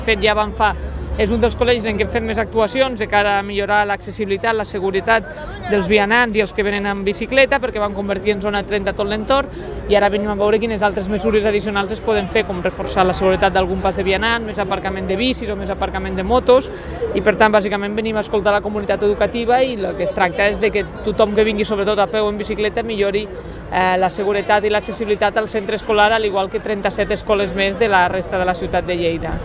arxiu-de-so-de-la-1a-tinent-dalcalde-marta-camps